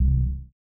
KIN Sub C1.wav